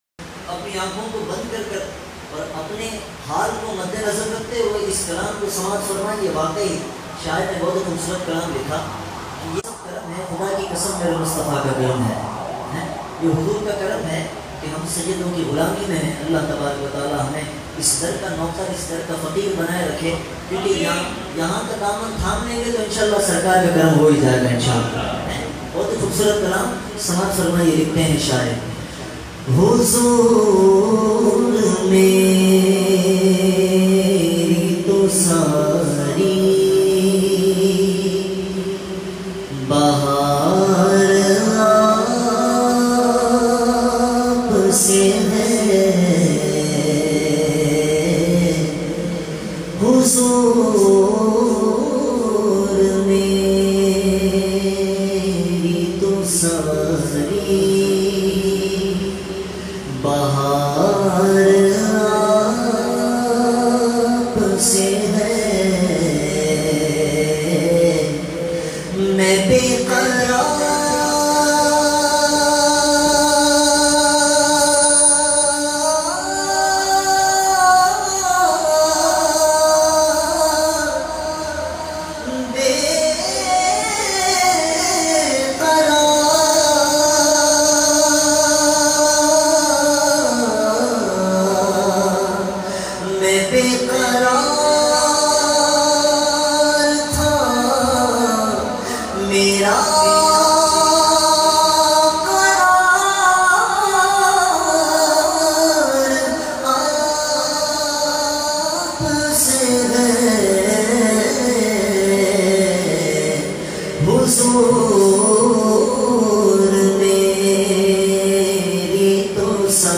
Heart-Touching Voice